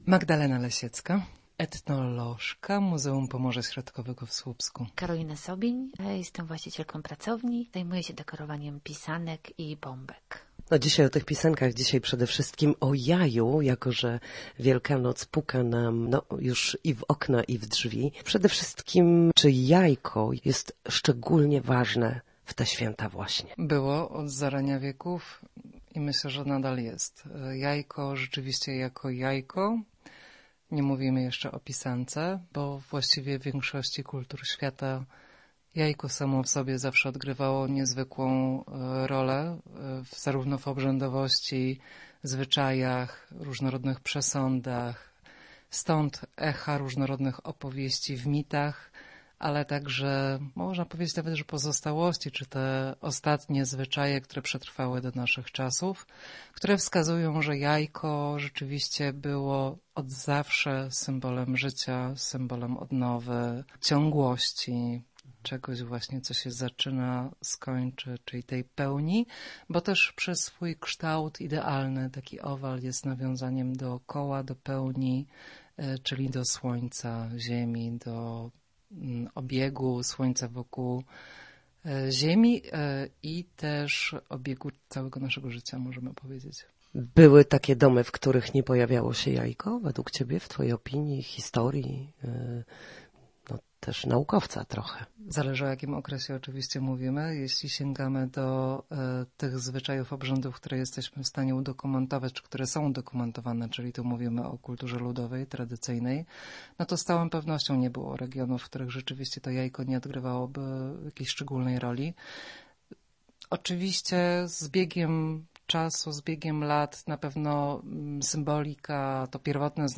Jakie znaczenie ma jajko w tradycji Wielkanocy? Na ten temat rozmawiały w Studiu Słupsk